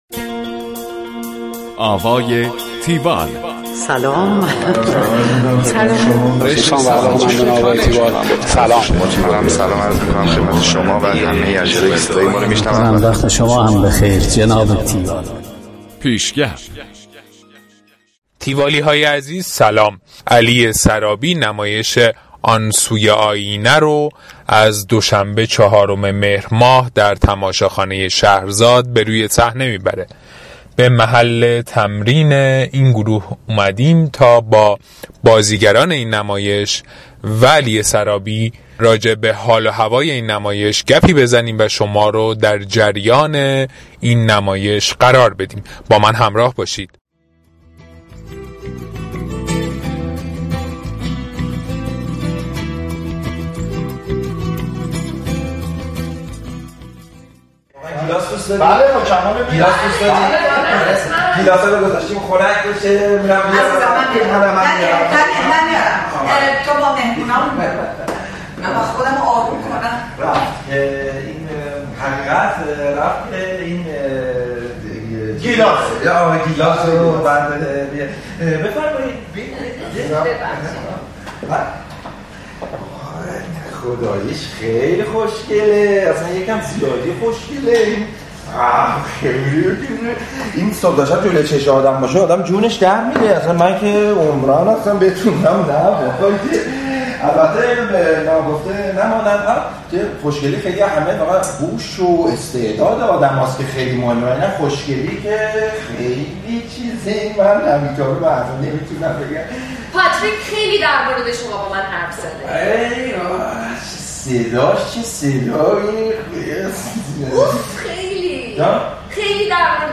گزارش آوای تیوال از نمایش آن سوی آینه